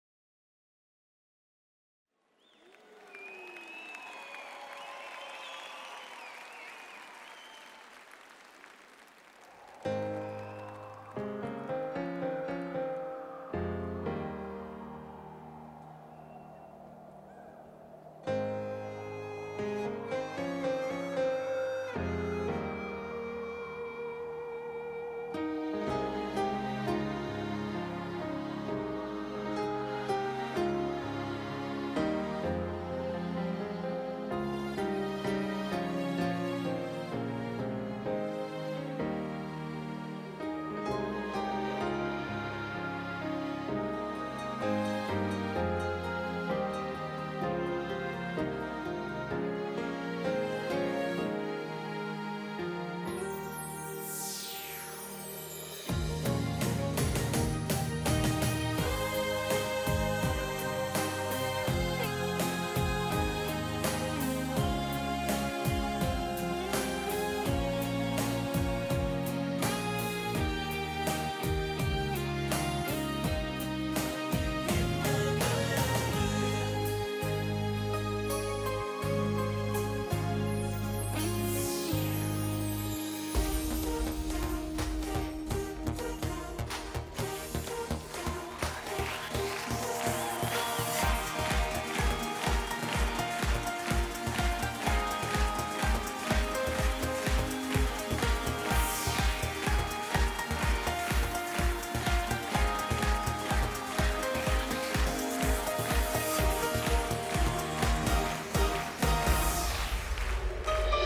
Genre: Pop, Schlager